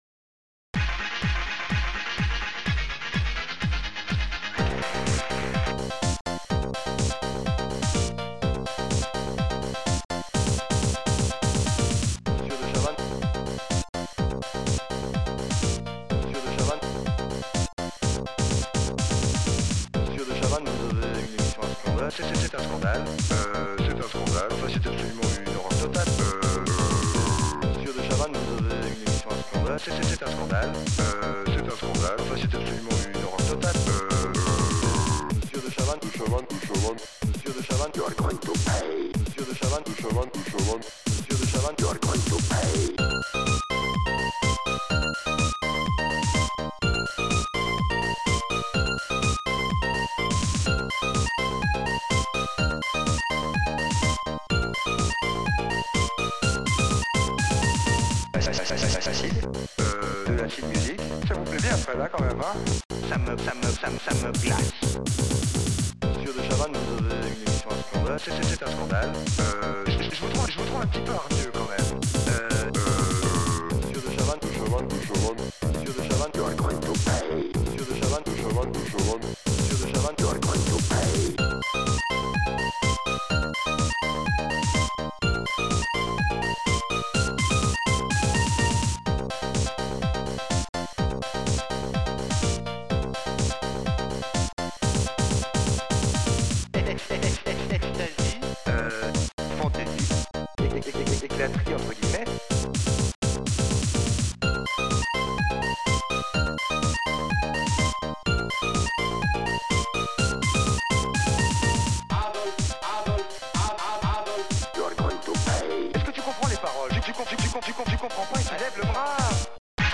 Protracker Module  |  1990-12-17  |  352KB  |  2 channels  |  44,100 sample rate  |  3 minutes, 5 seconds
ST-00:bassdrum
ST-00:snaredrum
ST-00:acc piano
ST-01:DeepBass